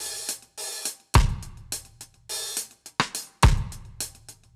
Index of /musicradar/dub-drums-samples/105bpm
Db_DrumsA_Dry_105_02.wav